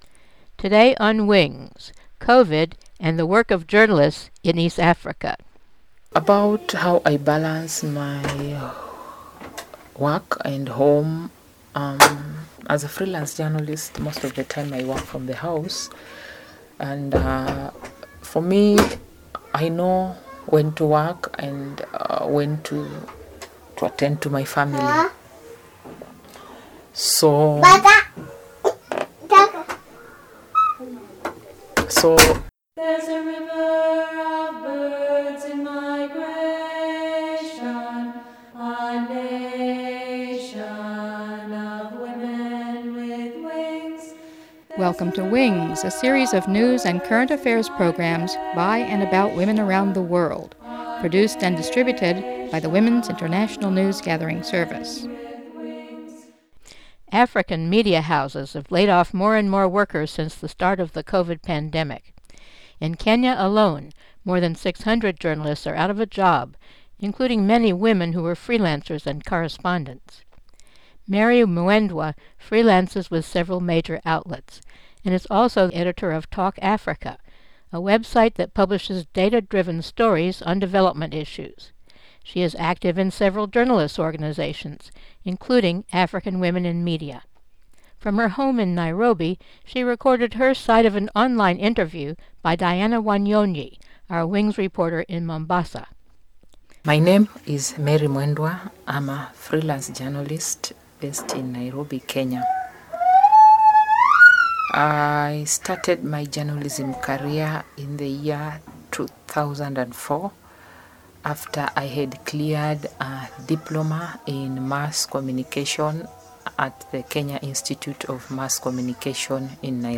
Mono
Interview